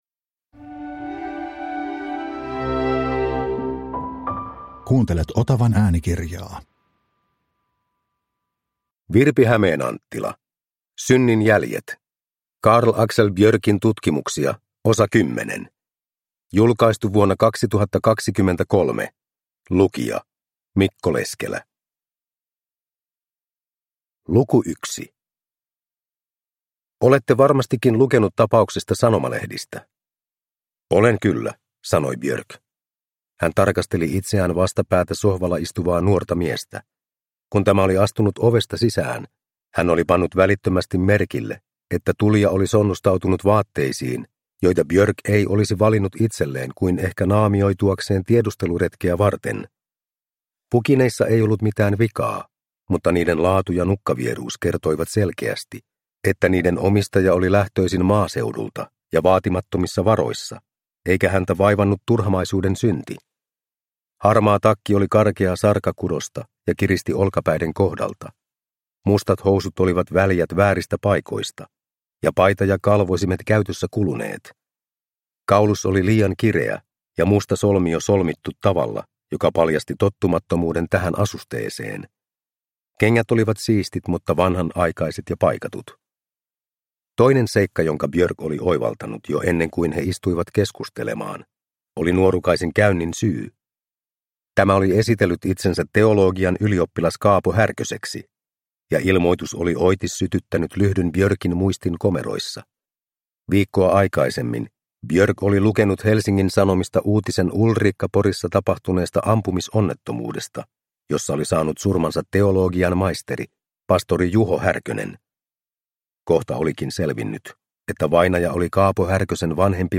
Synnin jäljet – Ljudbok – Laddas ner